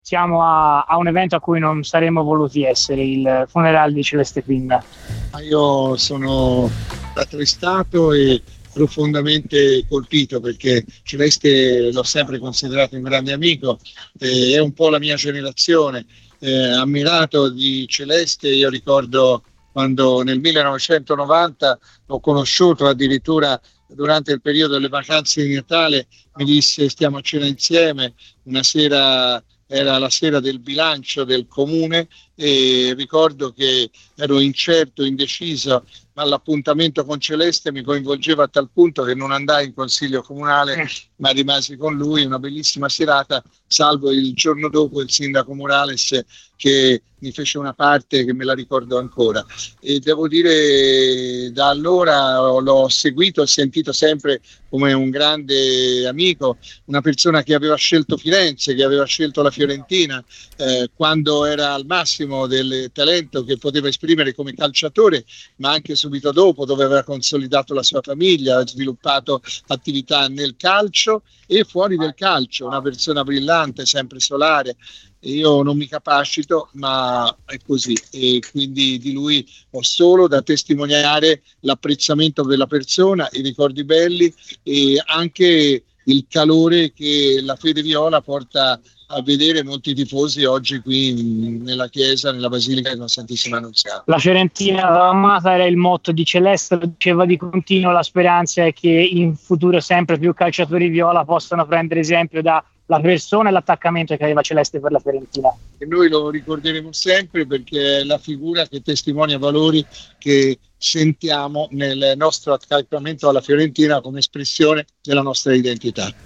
Il governatore della regione Toscana Eugenio Giani, presente ai funerali di Celeste Pin questo pomeriggio nella Basilica della Santissima Annunziata a Firenze, ha rilasciato alune dichiarazioni ai microfoni di Radio FirenzeViola.